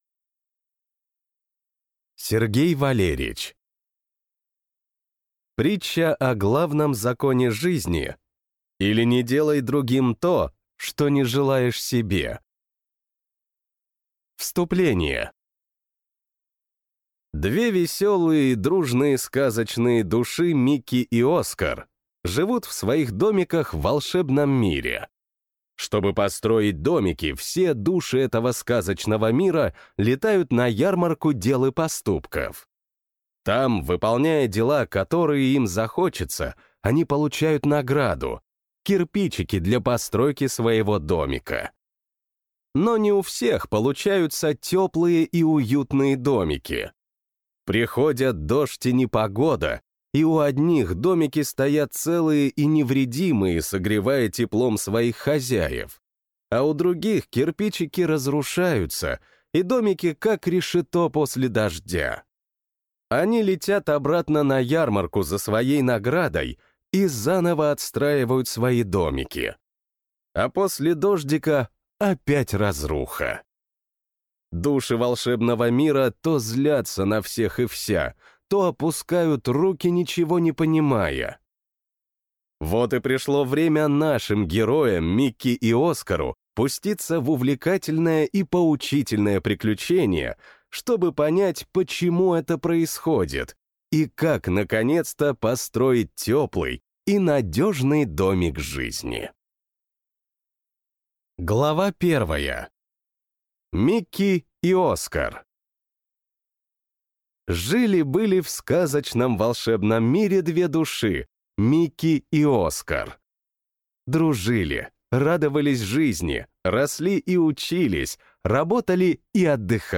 Аудиокнига Притча о главном законе жизни, или Не делай другим то, что не желаешь себе!